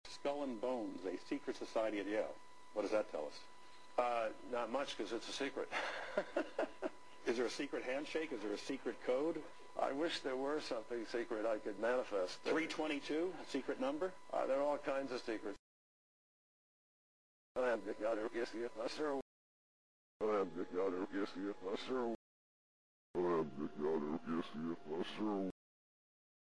私は、自分の魂(ソウル)をあなたに与える神です（I am the God who gives you my soul）】 というリバース・スピーチが浮かび上がった。